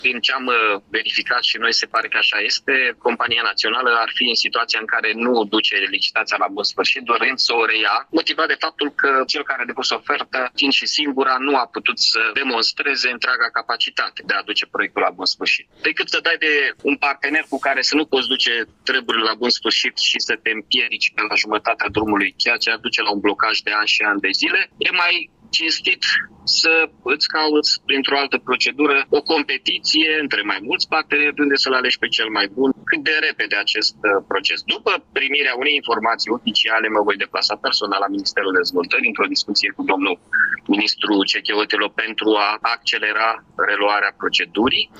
Primarul Mihai Chirica a confirmat informația pentru postul nostru de radio, însă a menționat că nu există încă o înștiințare oficială.